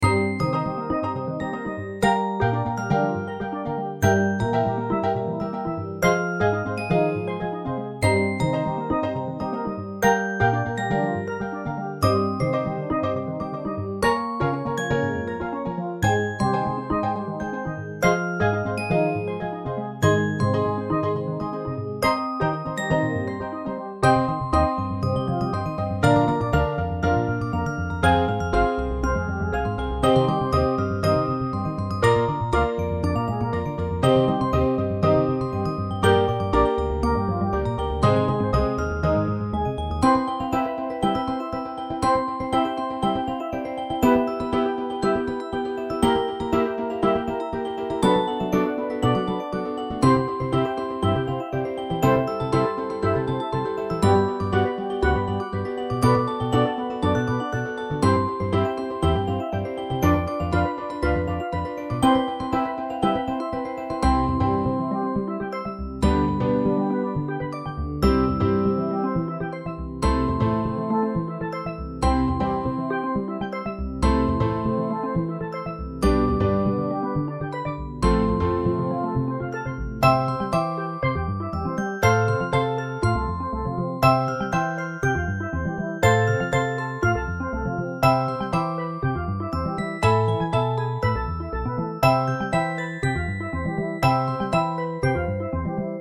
ミュージックボックス、スティールドラム、アコースティックベース、ピアノ
種類BGM